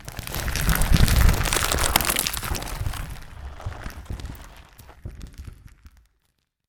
Rocks